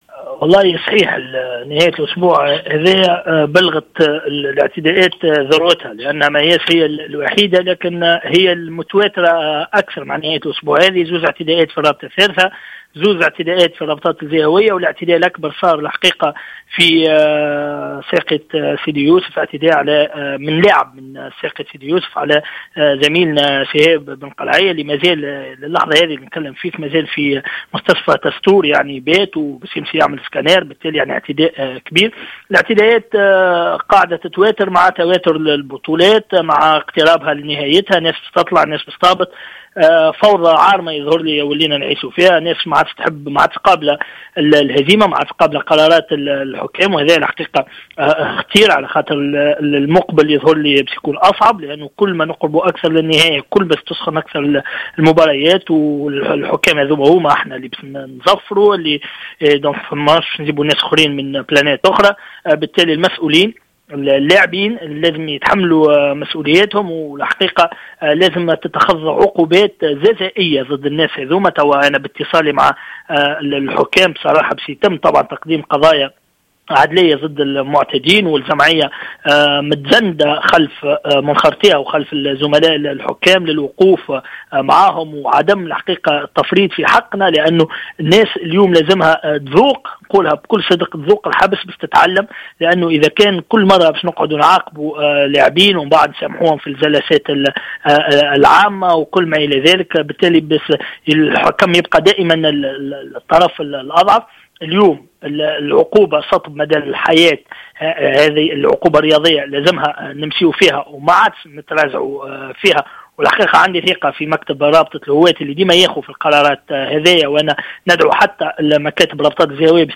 في تصريح لراديو جوهرة أف أم